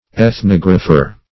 \Eth*nog"ra*pher\
ethnographer.mp3